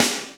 Index of /90_sSampleCDs/Roland L-CDX-01/SNR_Snares 7/SNR_Sn Modules 7
SNR ROCKE0CR.wav